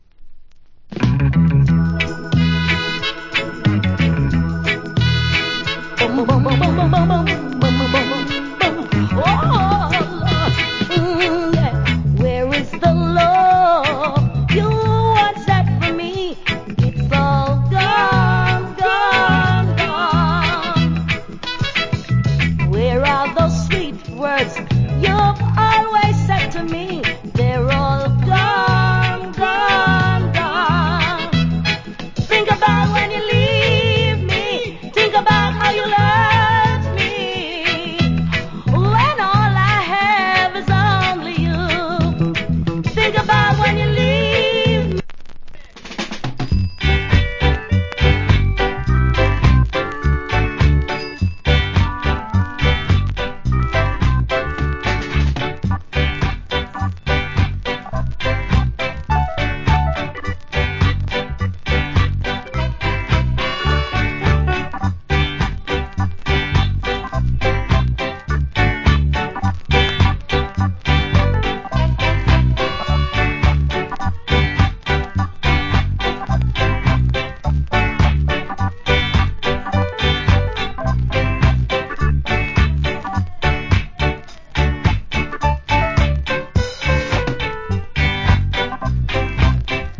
Wicked Female Reggae Vocal.